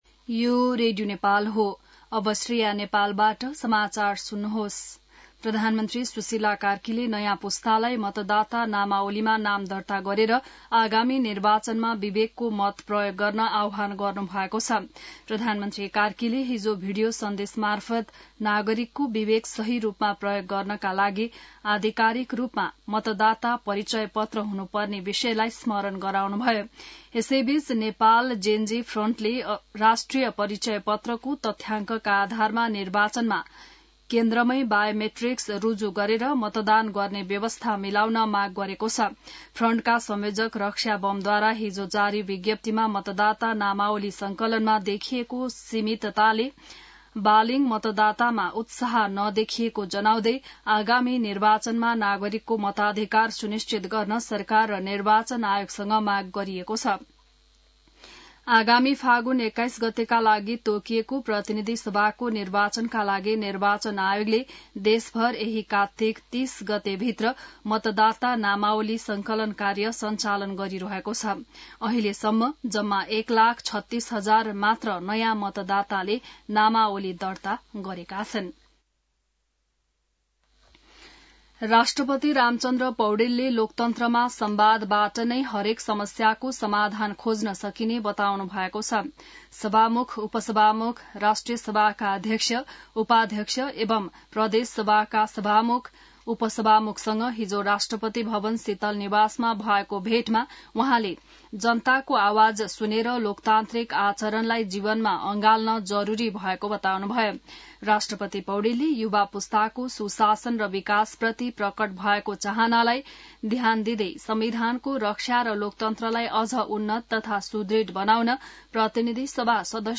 बिहान ६ बजेको नेपाली समाचार : २२ कार्तिक , २०८२